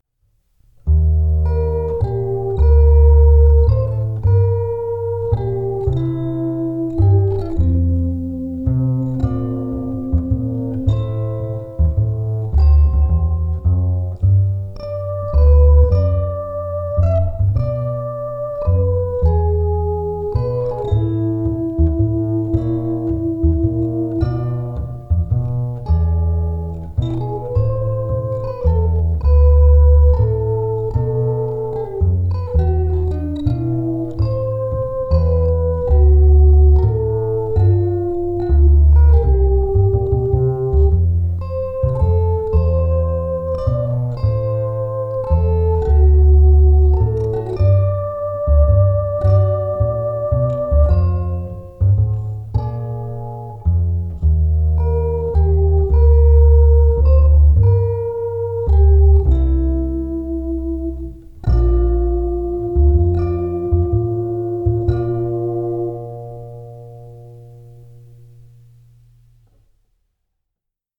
[♪] M's Dream VS1053b MIDI Flute, Electric Ukulele & Bass '24.07.06, 14 宅録
また、圧電素子を使ったピックアップの音がカリカリ。
これはこれで、なかなか柔らかい感じの音で良い感じです、と自画自賛(笑)。
なのでキーがコロコロと変わります(笑)。